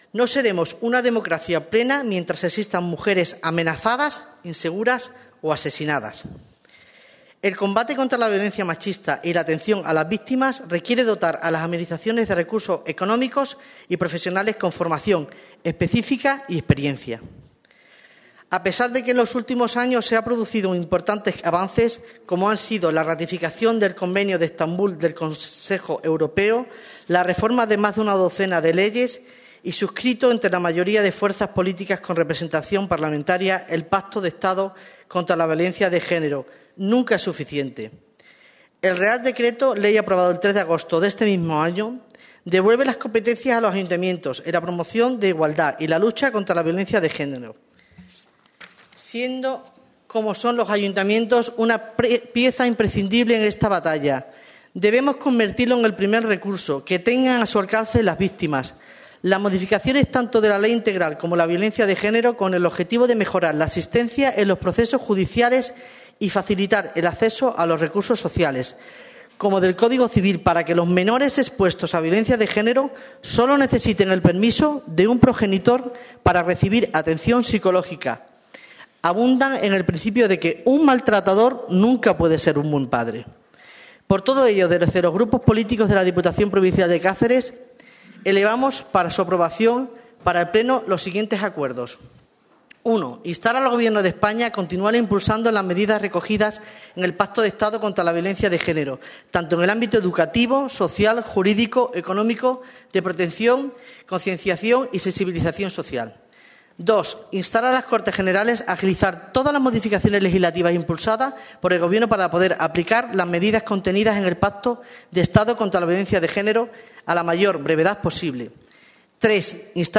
Con la presencia de trabajadores y trabajadoras de la Diputación, el Grupo Socialista, el Grupo Popular y el Grupo Ciudadanos han aprobado el siguiente Manfiesto, leído por la diputada de Igualdad, Marifé Plata.
CORTES DE VOZ